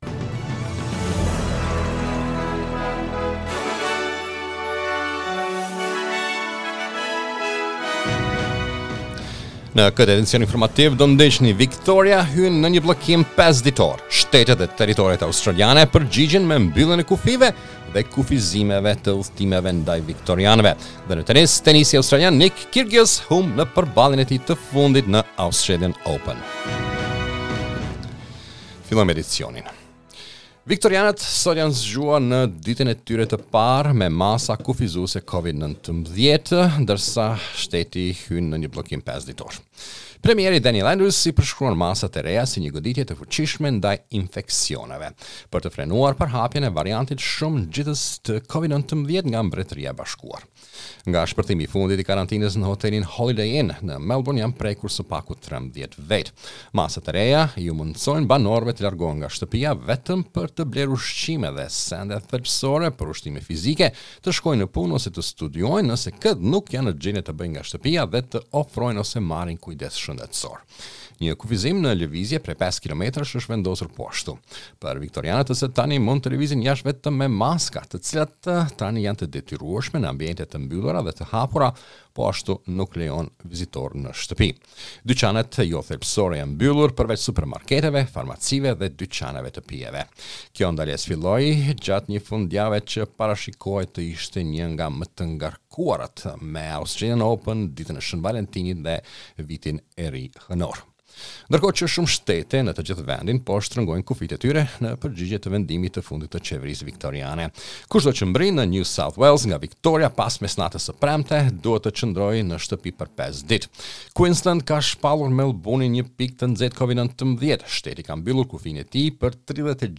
SBS News Bulletin in Albanian - 13 February 2021